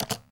terminal_button02.ogg